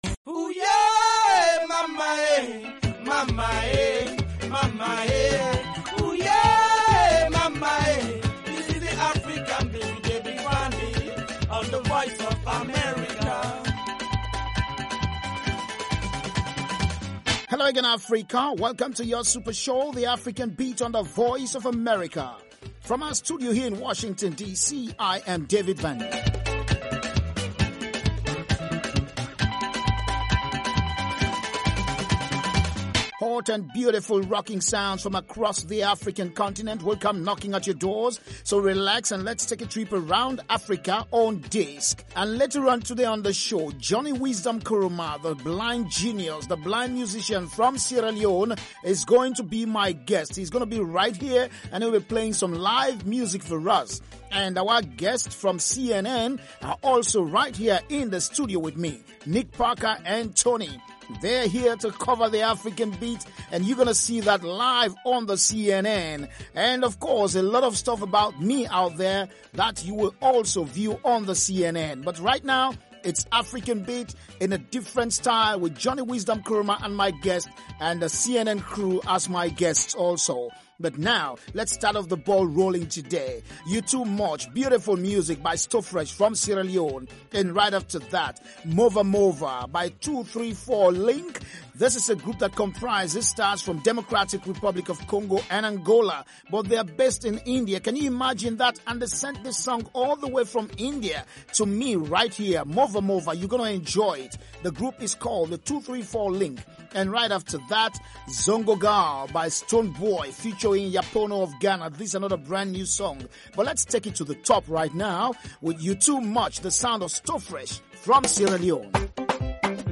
African Beat showcases the latest and the greatest of contemporary African music and conversation.